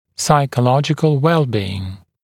[ˌsaɪkə’lɔʤɪkl ‘welˌbiːɪŋ][ˌсайкэ’лоджикл ‘уэлˌби:ин]психологическое здоровье